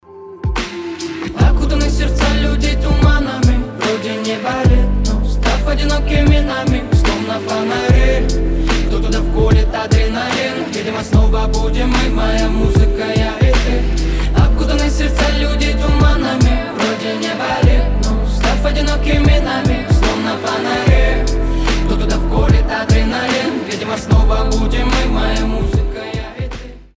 • Качество: 320, Stereo
красивые
лирика
Хип-хоп
атмосферные
спокойные
биты